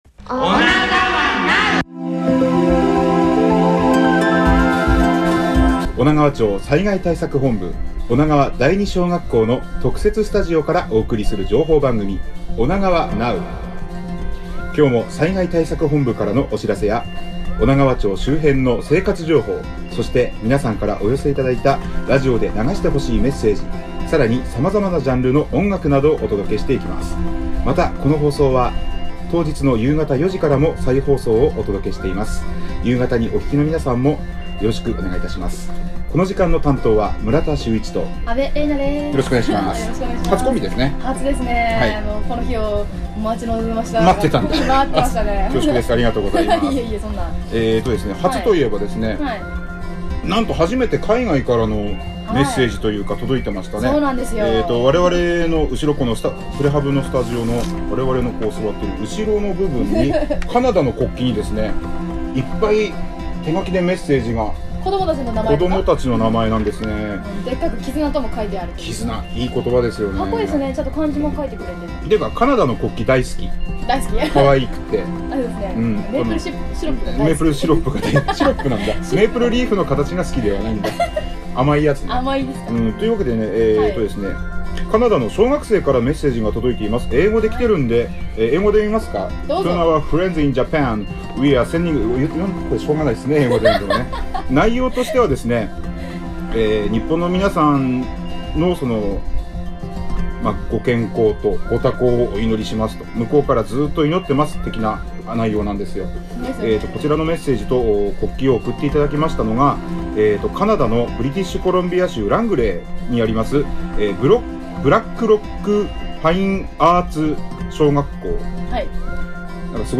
（５月１９日１３時～生放送したものを BGMなど一部差し替えています。）